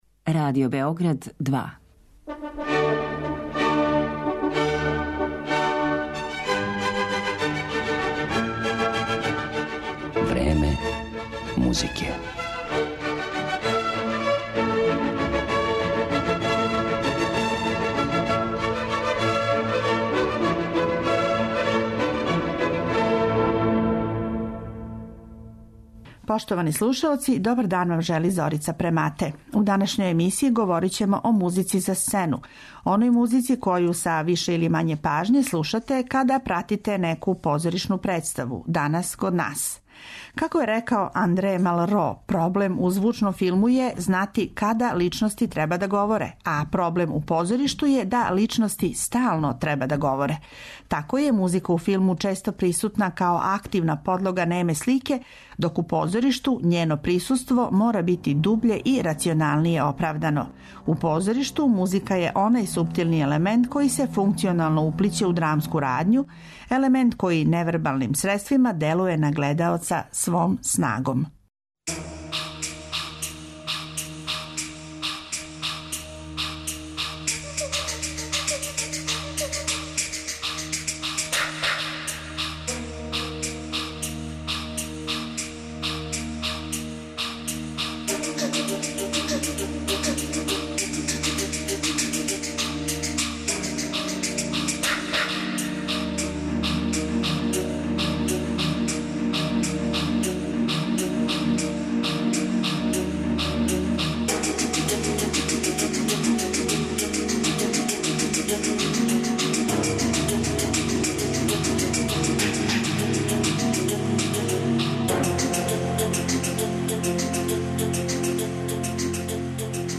Чућете њихову сценску музику насталу током последње две деценије, али и њихова дела за традиционални концертни подијум у којима су делотворно и стваралачки убедљиво обрадили и поново употребили исти властити тонски материјал који су преузели из позоришног жанра.